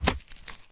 Monster_03_Hit.wav